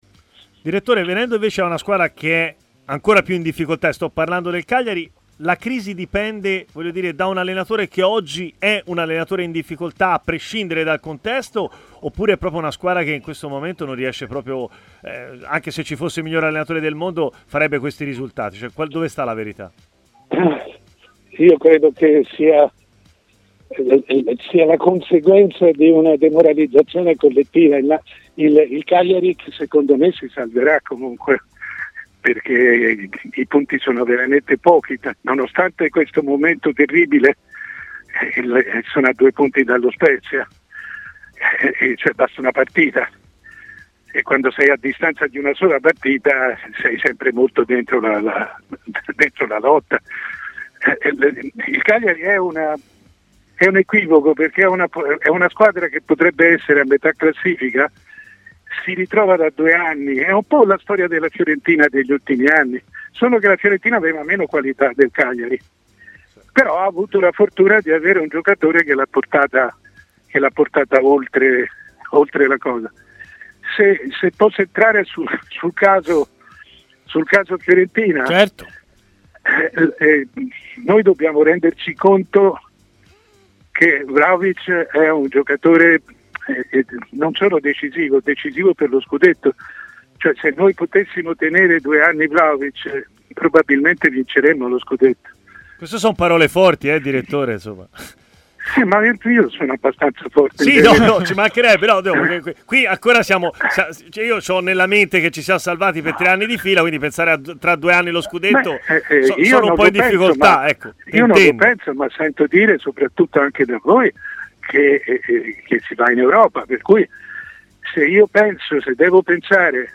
Mario Sconcerti, giornalista ed ex dirigente della Fiorentina, durante il programma Stadio Aperto di TMW Radio ha parlato anche di Dusan Vlahovic e delle sue enormi qualità: "Dobbiamo renderci contro che Vlahovic è un giocatore non solo decisivo, ma decisivo per lo scudetto: se lo tenessimo, probabilmente entro due anni vinceremmo lo scudetto.